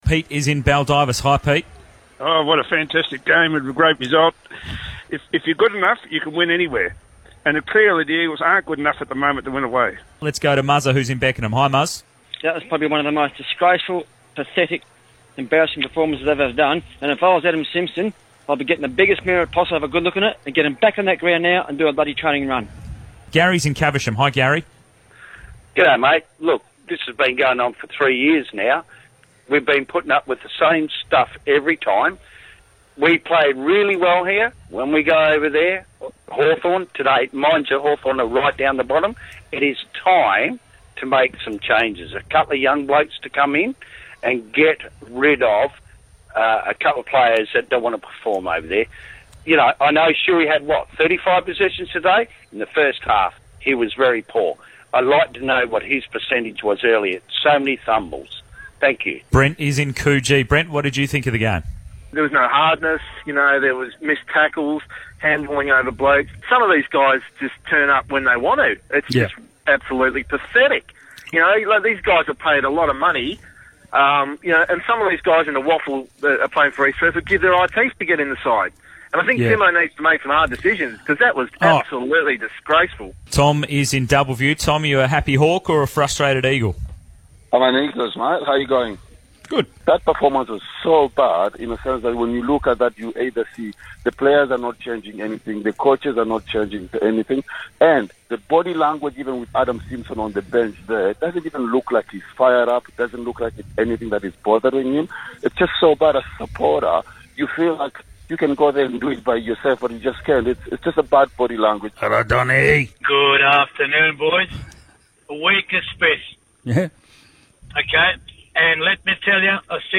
Callers post-game Hawks vs Eagles